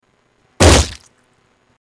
Body Fall Splat